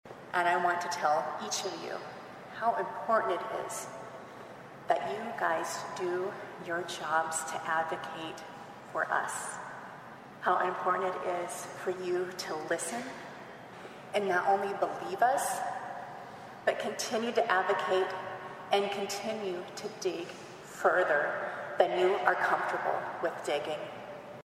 THIS IS NATIONAL CRIME VICTIMS’ RIGHTS WEEK AND WOODBURY COUNTY OFFICIALS HELD A GATHERING AT THE COURTHOUSE THURSDAY TO CALL ATTENTION TO LOCAL VICTIMS AND WHAT THEY HAVE BEEN THROUGH.